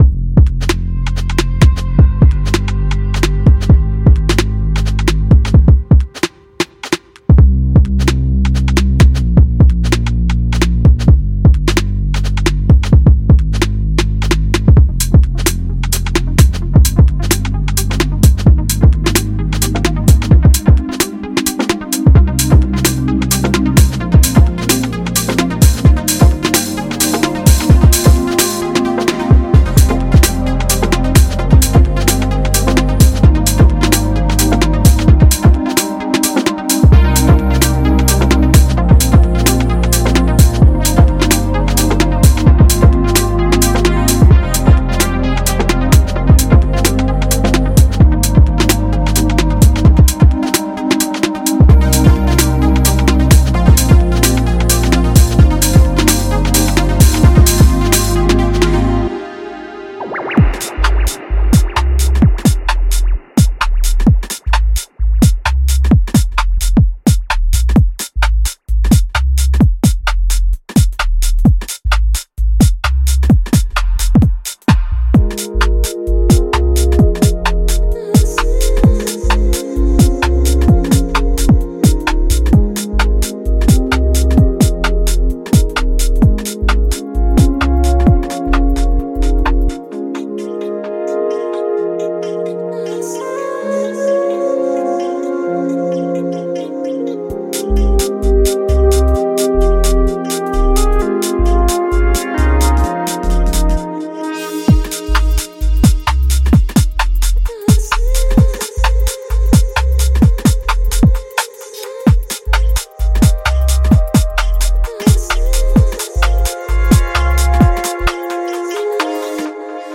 丰富的声音元素包括反射性垫音、跳跃的节奏、分层的大气音效、温暖的低音和有力的鼓循环，能够为您的音乐作品增添独特的色彩。
声道数：立体声